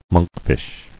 [monk·fish]